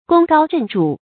功高震主 gōng gāo zhèn zhǔ
功高震主发音